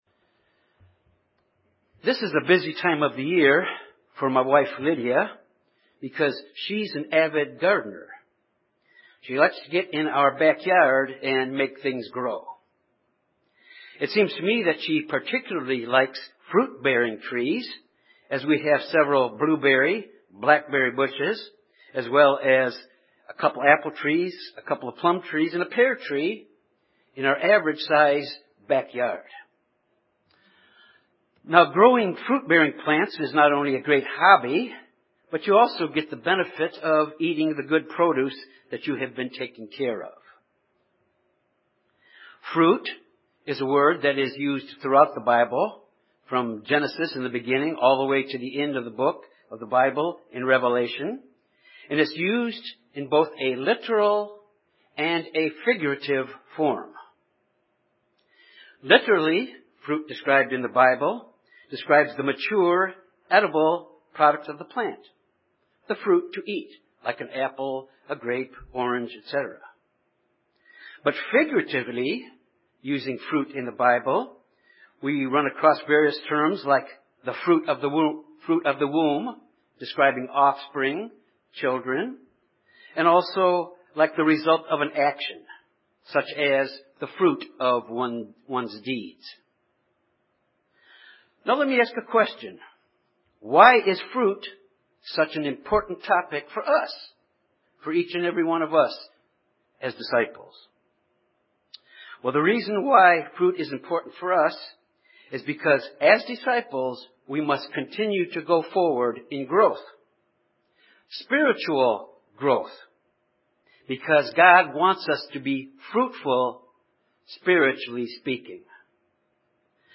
This sermon examines three keys to produce fruit as disciples of Christ by means of God’s Holy Spirit.